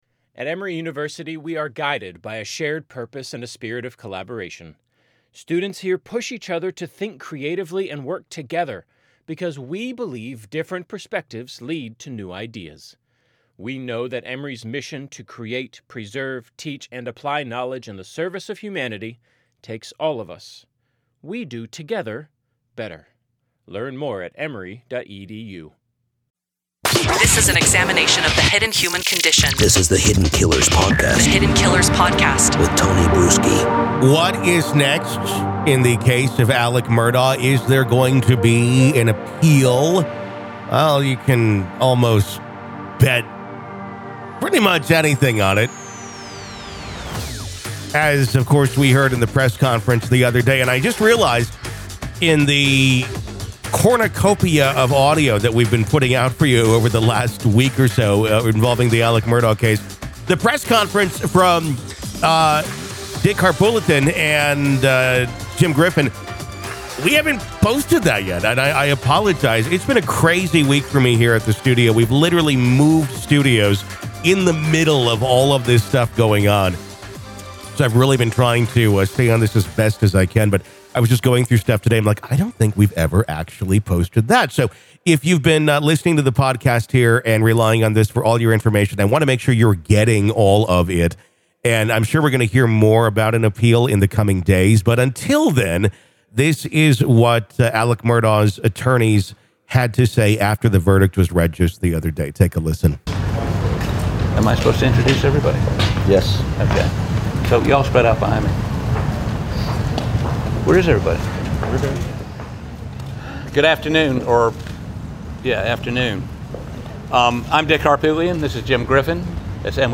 held a press conference following the sentencing of their client for a high-profile murder case.